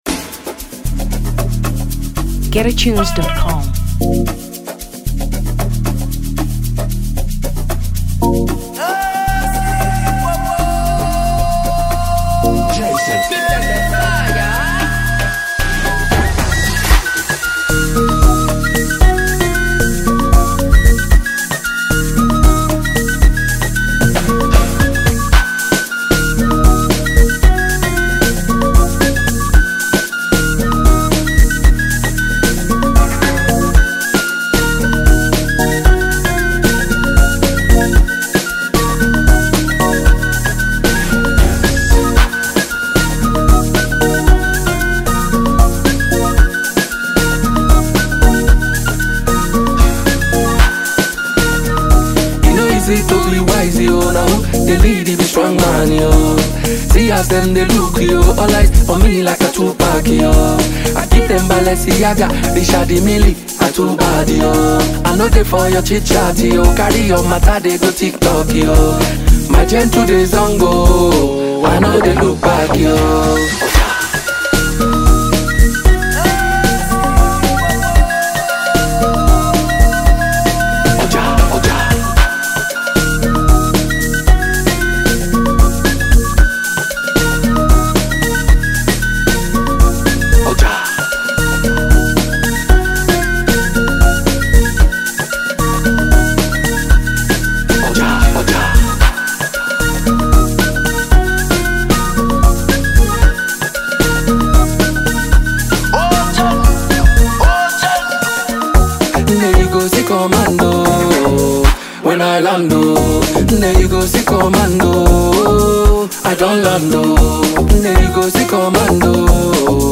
Amapiano 2023 Nigeria